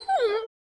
monster / wolf / damage_1.wav
damage_1.wav